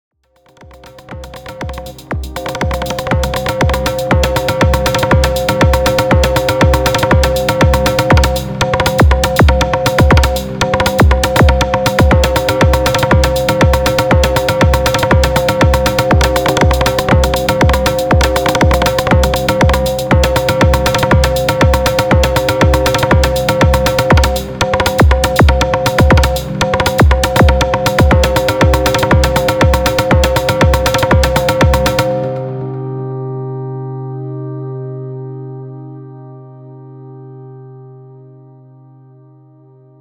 Syntakt grandfather, M:C, can produce groovy percussion lines with their macros linked to velocity on the pads + some retriggs: